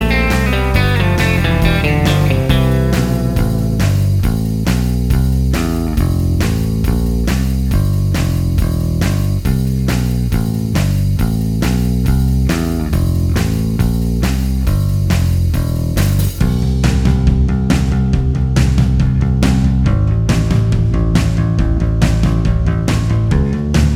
Minus Rhythm Guitar Pop (1980s) 3:15 Buy £1.50